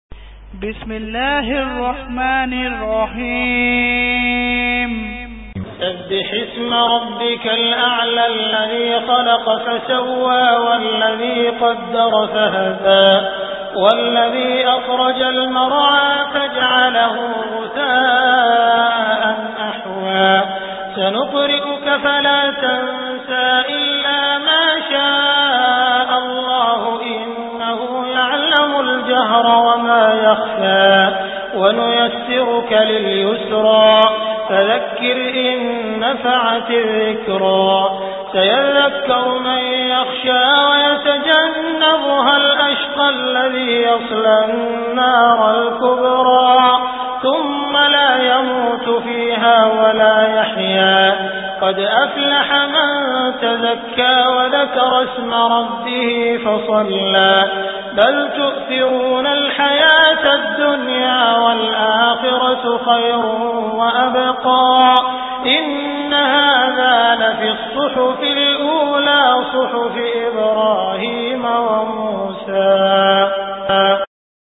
Surah Al Ala Beautiful Recitation MP3 Download By Abdul Rahman Al Sudais in best audio quality.